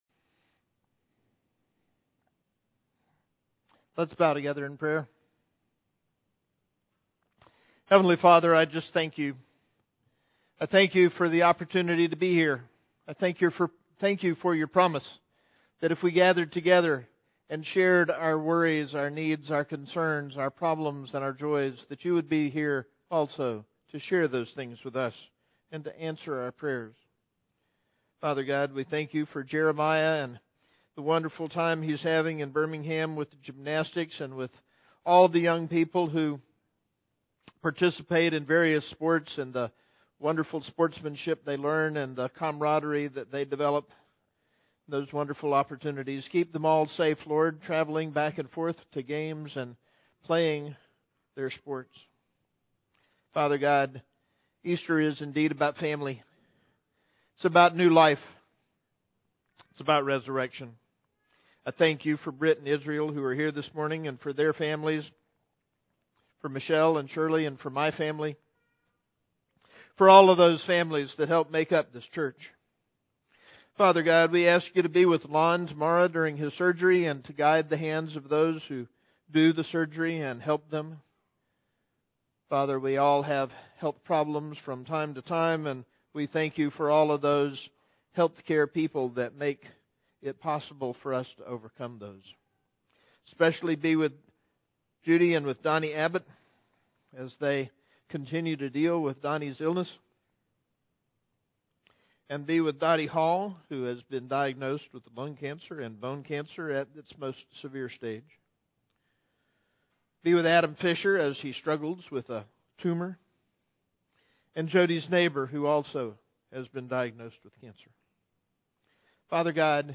Pastoral Prayer